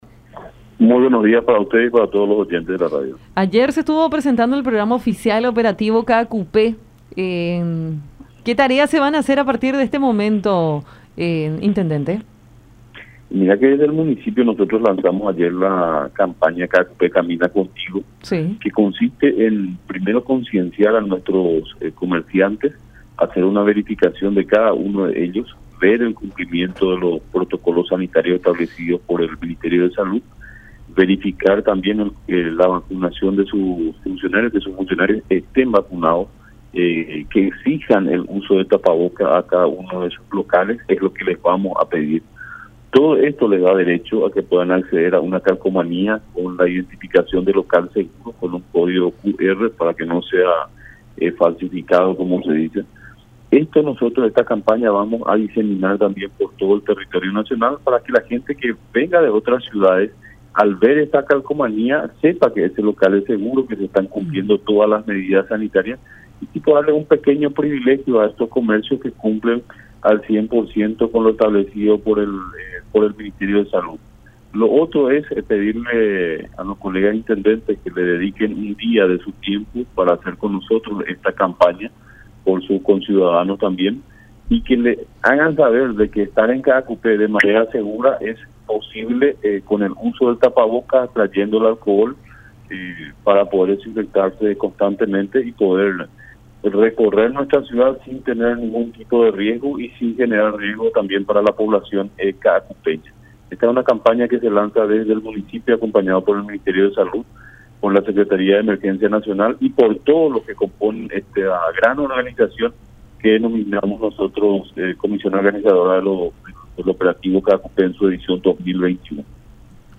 Eso instamos a la conciencia de la gente para que esto finalmente se pueda cumplir a cabalidad”, aseveró Riveros en diálogo con Enfoque 800 a través de La Unión.